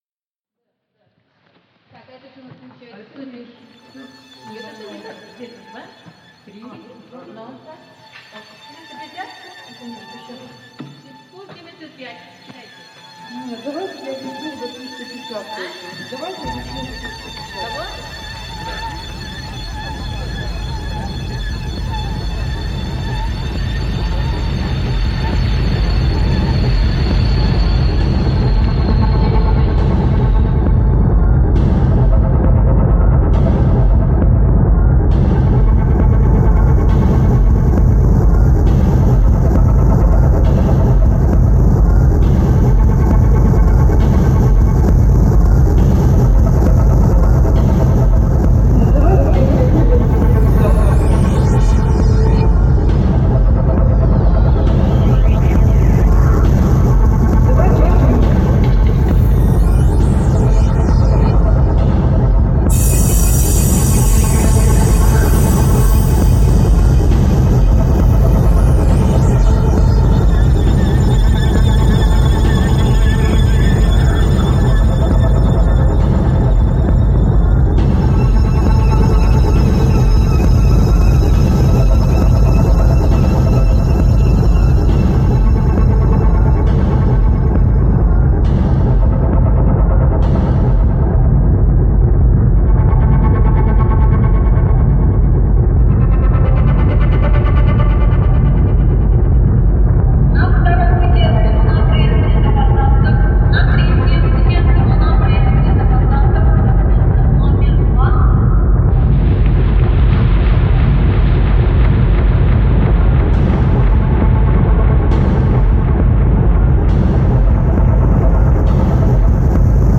Zima train station, Siberia